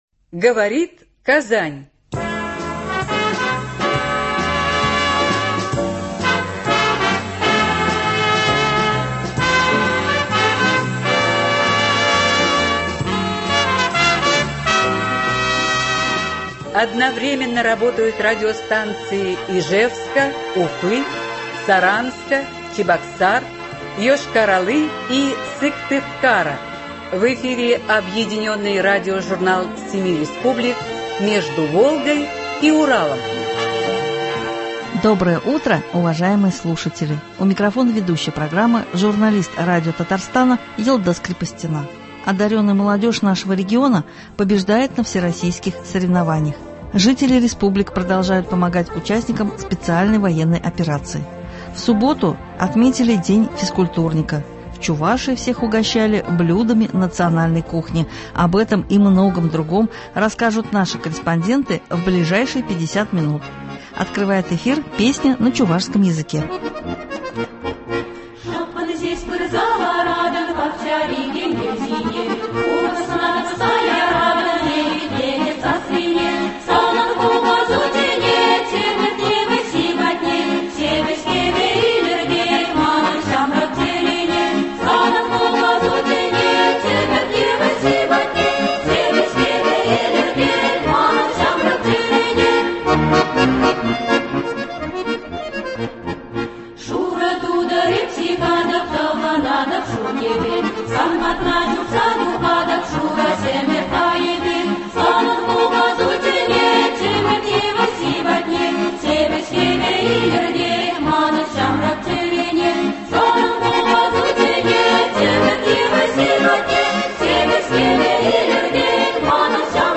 Объединенный радиожурнал семи республик.
Одаренная молодежь нашего региона побеждает на всероссийских соревнованиях, жители республик продолжают помогать участникам СВО, в субботу отметили День физкультурника, в Чувашии всех угощали блюдами национальной кухни. Об этом и многом другом расскажут наши корреспонденты в ближайшие 50 минут.